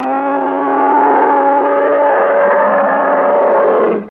Monster Roar Long